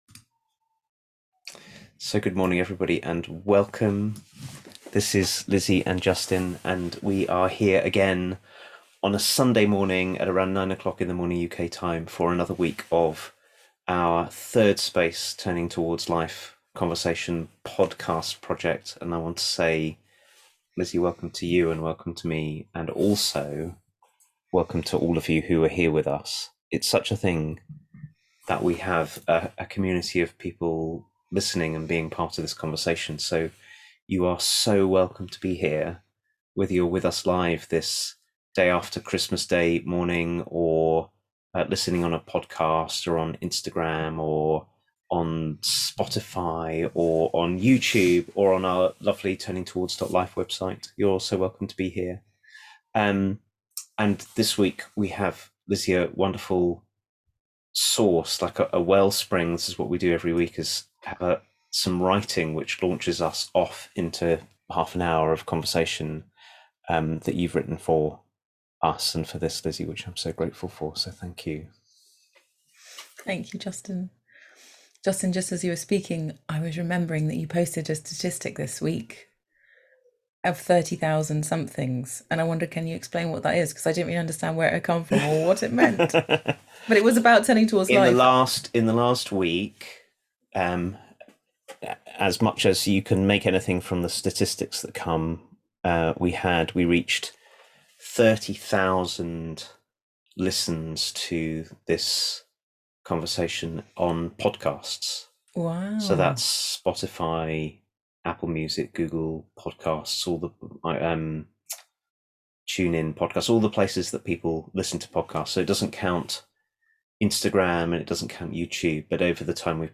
This week's Turning Towards Life is a conversation about profound ways we affect one another before we even speak.